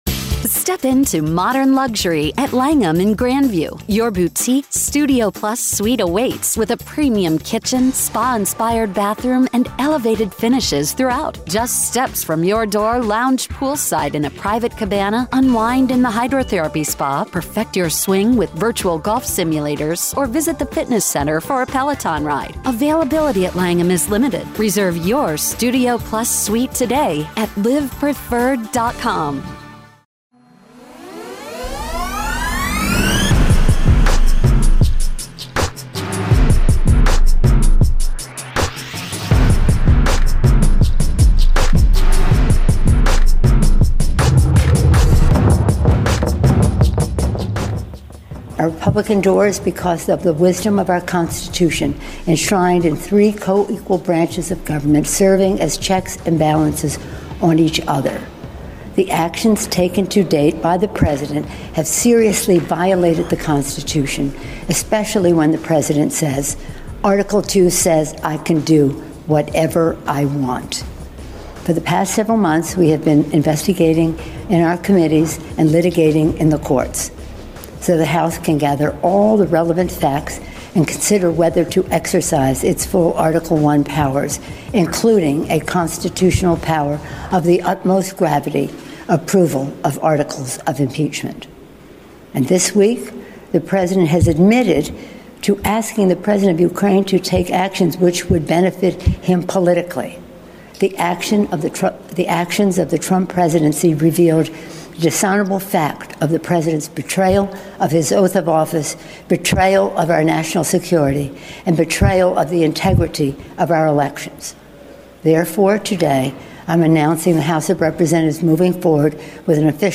With Guest Phil Mudd